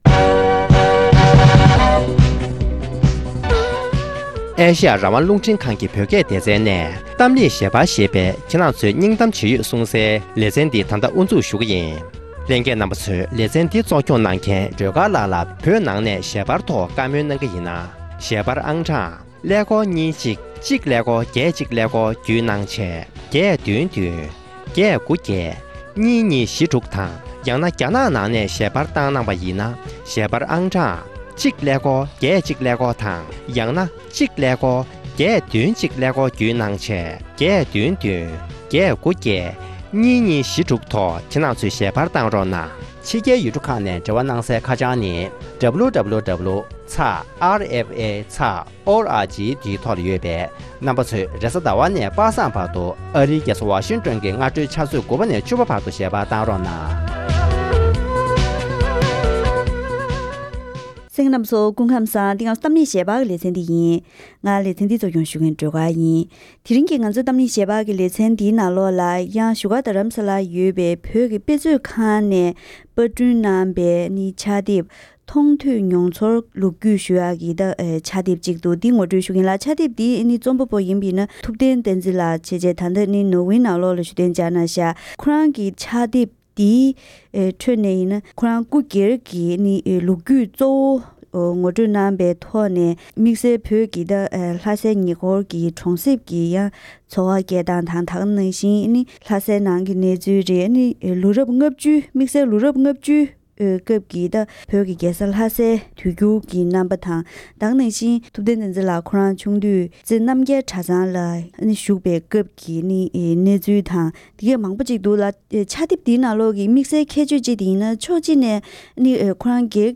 གླེང་མོལ་གྱི་ལས་རིམ།